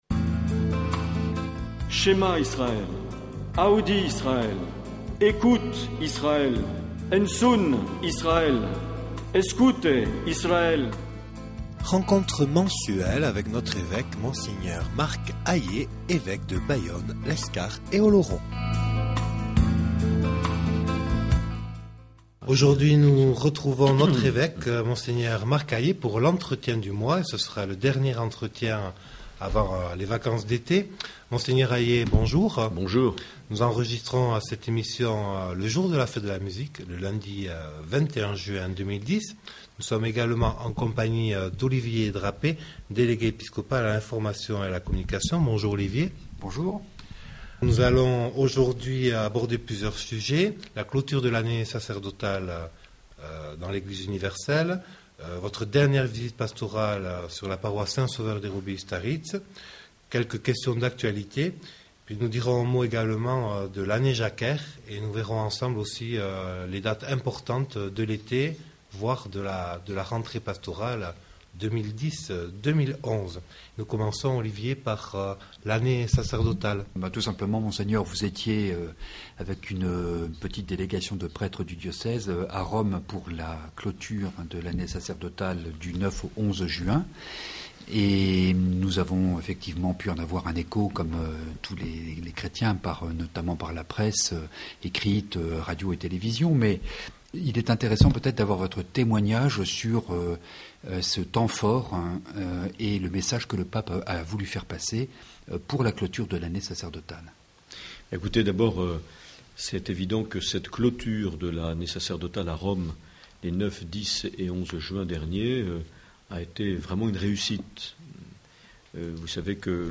Les entretiens
Une émission présentée par Monseigneur Marc Aillet